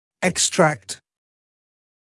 [ɪk’strækt][ик’стрэкт]удалять, удалить (о зубах)